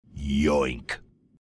halo reach yoink voice
halo-reach-yoink-voice.mp3